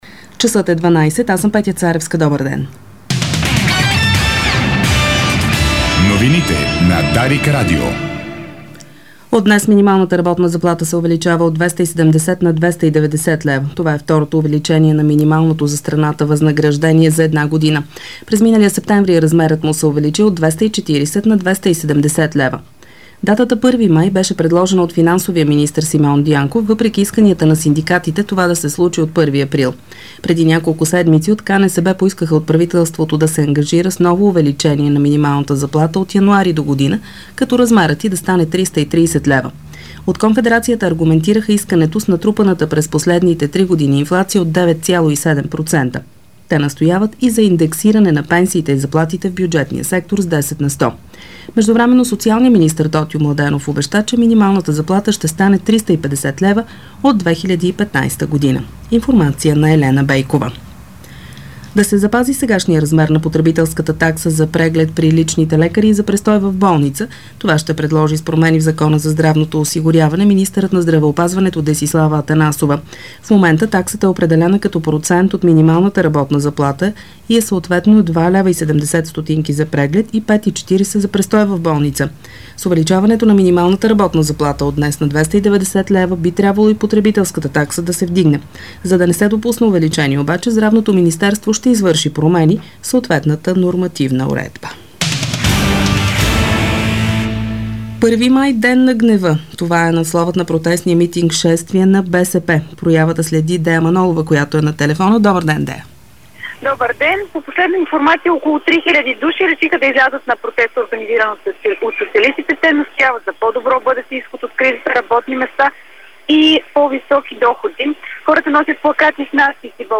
Обедна информационна емисия - 01.05.2012 г.